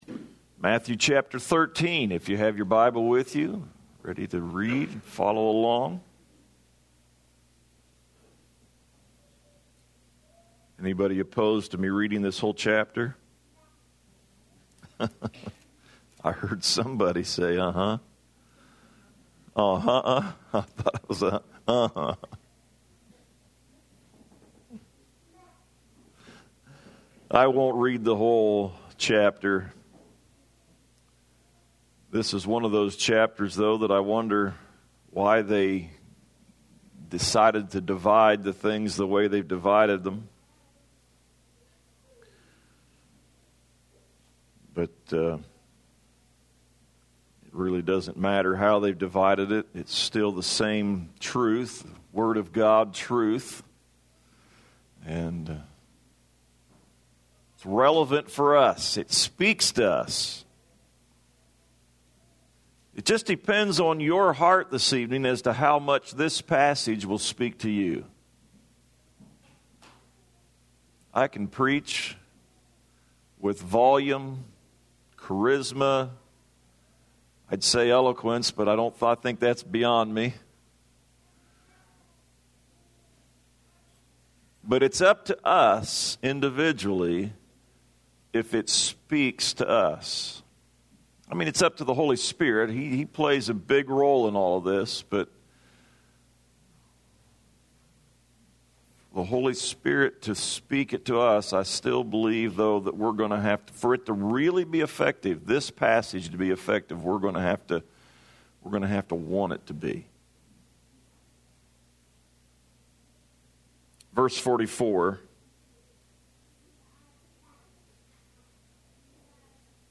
Salvation